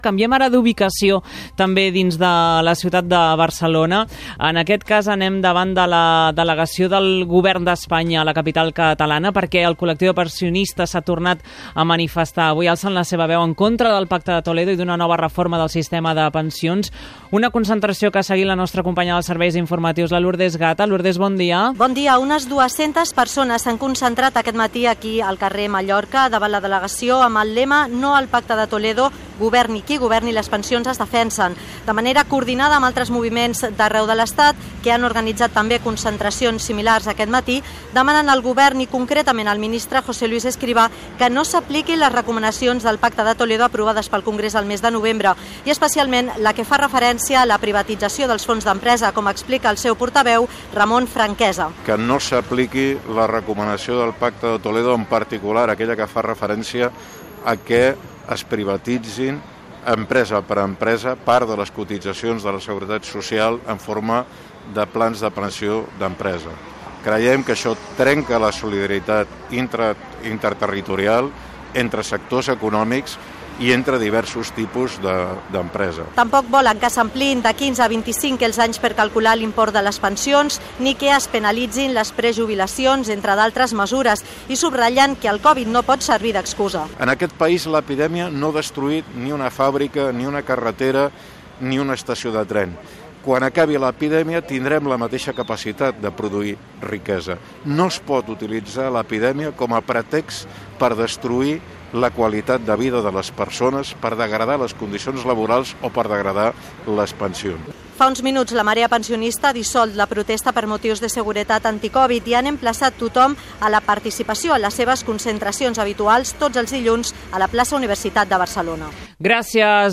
Connexió per informar sobre la manifestació de la marea pensionista de Catalunya a la ciutat de Barcelona
Info-entreteniment